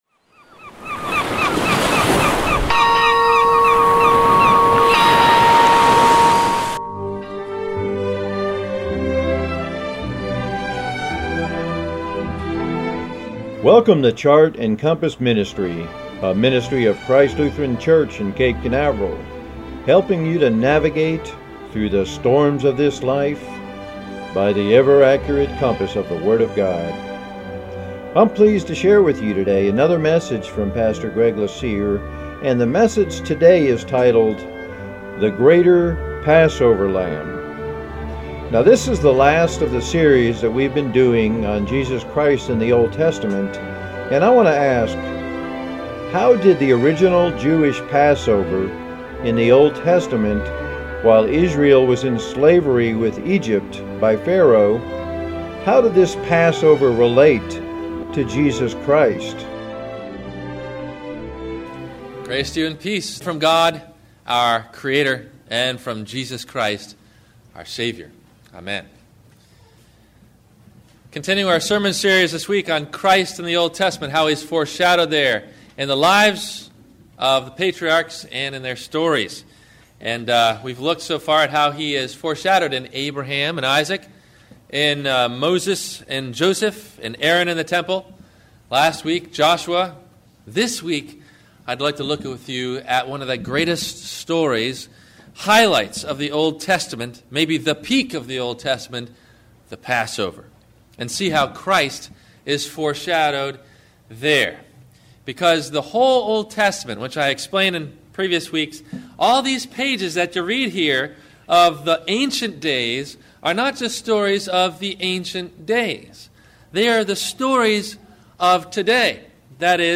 The Greater Passover Lamb - WMIE Radio Sermon – April 11 2016 - Christ Lutheran Cape Canaveral